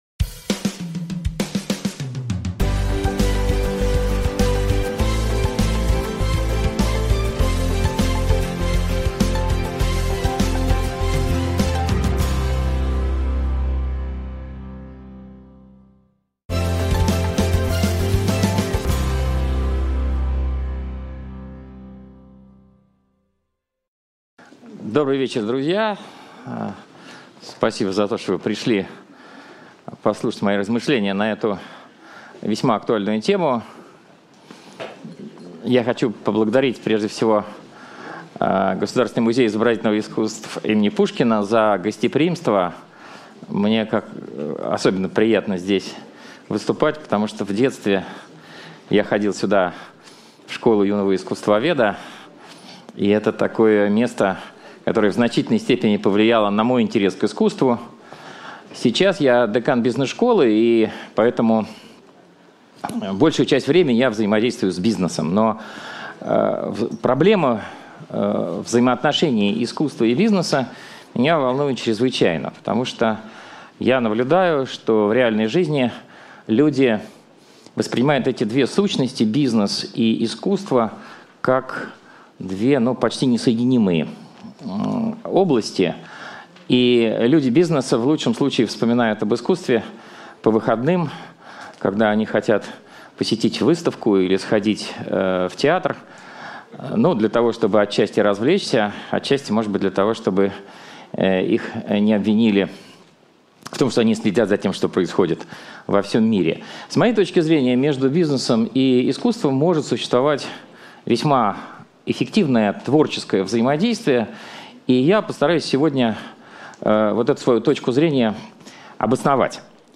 Аудиокнига Бизнес и искусство: возможности и проблемы взаимодействия | Библиотека аудиокниг